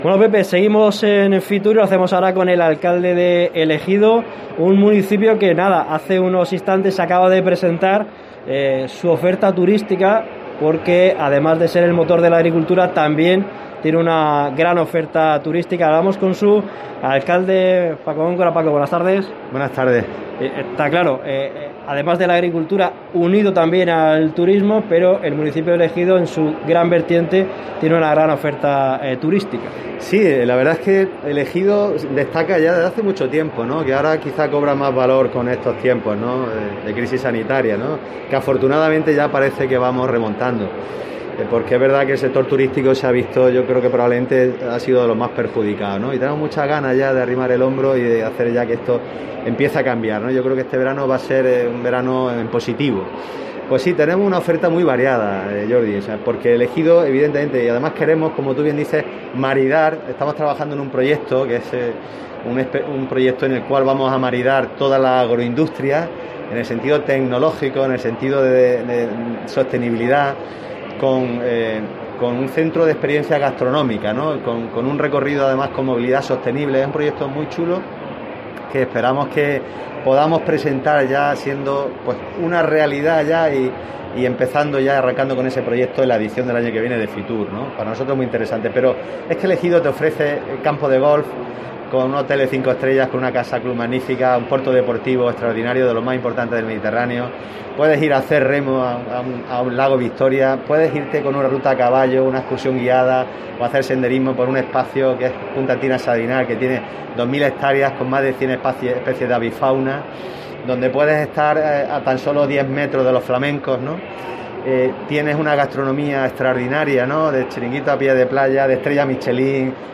El alcalde de El Ejido ha presentado la oferta turísitica de su municipio en FITUR y, en declaraciones a COPE Almería, invita a visitarlo "porque nadie se aburrirá"